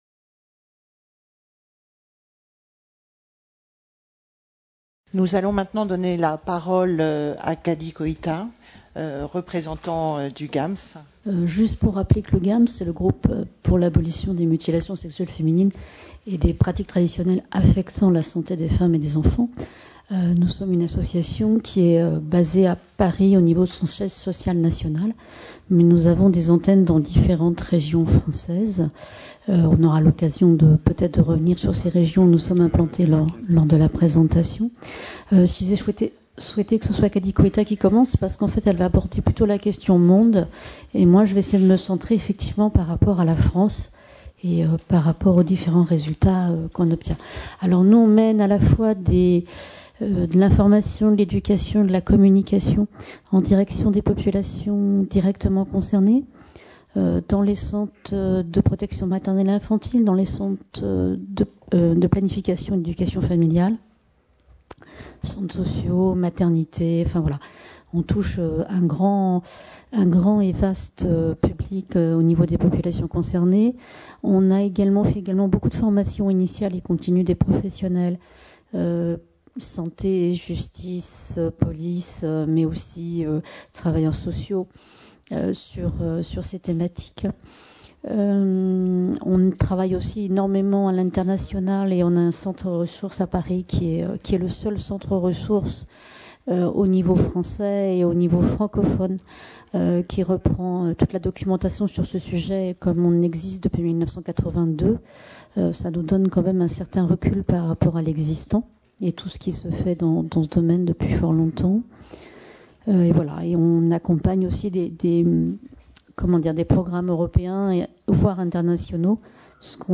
4ème Journée Humanitaire sur la Santé des Femmes, organisée par Gynécologie Sans Frontières, le 29 novembre 2013, au Palais du Luxembourg (Paris). De la culture traditionnelle à la mode, quand la chirurgie devient une autre forme de violence faite aux femmes.